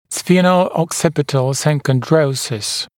[ˌsfiːnəuɔk’sɪpɪtl ˌsɪŋkɔnˈdrəusɪs][ˌсфи:ноуок’сипитл ˌсинконˈдроусис]клиновидно-затылочный синхондроз